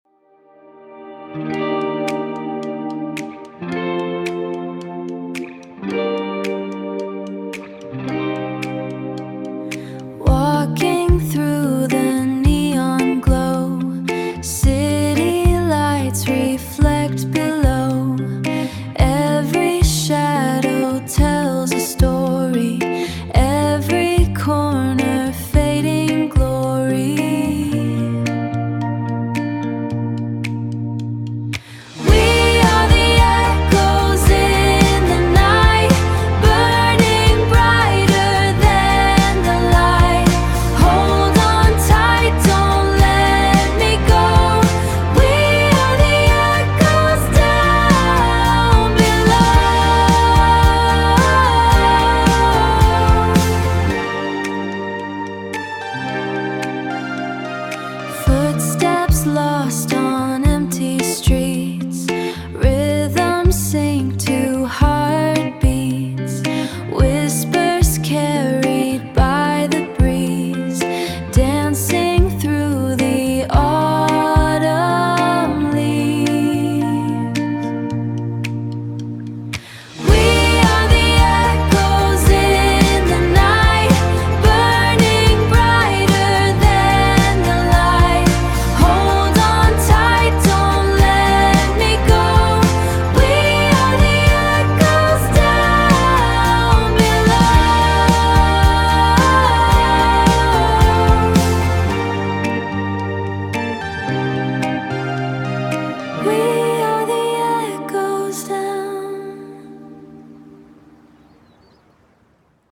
image-to-music music-generation
Genre: Dreamy indie pop. Mood: Nostalgic and uplifting. Tempo: 110 BPM.
bpm: 110.0
[0.0:] A dreamy, shimmering Indie Pop introduction defined by its hazy, nostalgic atmosphere. The segment is anchored by a solo electric guitar playing clean, jazzy chords with a heavy chorus and vibrato effect. The rhythm is light and unpercussive, featuring only a steady, clicking wooden rim-shot on every second beat. The instrumentation is sparse, focusing on the watery guitar tone and a very soft, high-pitched synthesizer arpeggio that mimics the twinkling of neon lights. There are no vocals in this segment. The melodic structure is atmospheric and wandering. The interaction between the lush guitar effects and the subtle synth sparkles creates a sense of urban isolation. The atmosphere is quiet and observant, evoking a slow-motion walk through a glowing city at dusk, characteristic of the Dream Pop subgenre.
[10.9:] A dreamy, shimmering Indie Pop verse defined by its intimate, nocturnal mood. The segment is anchored by a solo electric guitar playing clean, jazzy chords with a heavy chorus effect. The rhythm is subtle, featuring a steady, ticking wooden rim-shot on every second beat and a very soft, muffled kick drum pulse on the downbeats. The instrumentation includes a gentle, warm analog bass synthesizer providing a soft low-end cushion and occasional twinkling digital synth accents. The vocal performance features a female soprano with an ethereal, breathy timbre and a soft, delicate delivery. Her style is intimate and conversational, processed with a light hall reverb to add depth. The melodic structure is simple and repetitive, characteristic of modern Dream Pop. The interaction between the ethereal voice and the clean, watery guitar tones creates a feeling of gentle melancholy. The atmosphere is nostalgic and bittersweet, evoking a walk through a city lit by neon signs.
[32.7:] A bright, anthemic Indie Pop chorus defined by its uplifting energy and rich, layered texture. The segment is anchored by a punchy drum kit playing a steady backbeat with a prominent, clicking hi-hat pattern. The rhythm is driving and propulsive. The instrumentation expands to include a melodic, fuzzed-out bass guitar and a wall of soaring, reverb-heavy synthesizer pads that fill the stereo field. A high-pitched, crystalline lead synth plays a counter-melody. The vocal performance features the female soprano with an ethereal, breathy timbre, now double-tracked and harmonized to create a wide, choral effect. She sings with more power and a sense of urgent longing. The melodic structure is soaring and memorable, typical of Jangle Pop and Indie Pop hooks. The interaction between the driving rhythm section and the wash of synthetic textures creates a cathartic peak. The atmosphere is celebratory and glowing, reminiscent of a shared secret under moonlight.
[58.9:] A dreamy, shimmering Indie Pop verse defined by its intimate, nocturnal mood. The segment is anchored by a solo electric guitar playing clean, jazzy chords with a heavy chorus effect. The rhythm is subtle, featuring a steady, ticking wooden rim-shot on every second beat and a very soft, muffled kick drum pulse on the downbeats. The instrumentation includes a gentle, warm analog bass synthesizer providing a soft low-end cushion and occasional twinkling digital synth accents. The vocal performance features a female soprano with an ethereal, breathy timbre and a soft, delicate delivery. Her style is intimate and conversational, processed with a light hall reverb to add depth. The melodic structure is simple and repetitive. The interaction between the ethereal voice and the clean, watery guitar tones creates a feeling of gentle melancholy. The atmosphere is nostalgic and bittersweet, characteristic of modern Indie Pop with Dream Pop sensibilities.